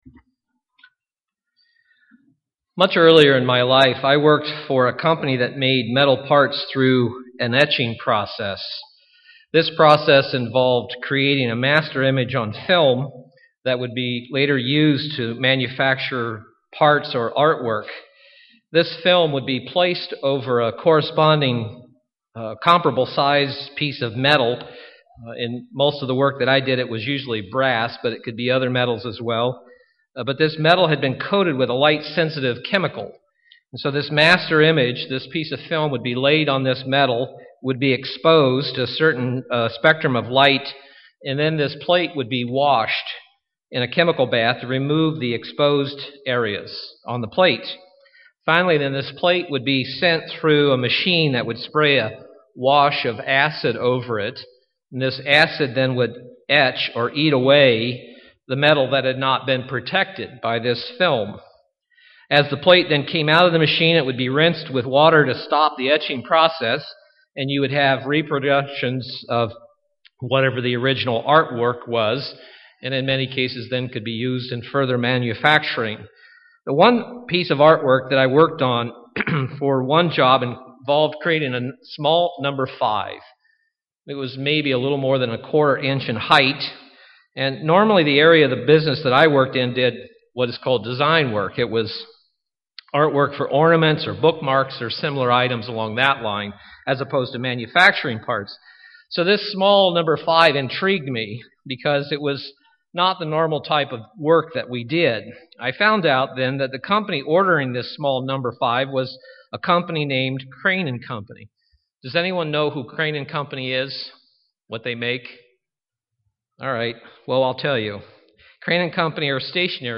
UCG Sermon Notes Much earlier in my life, I worked for a company that made metal parts through an etching process.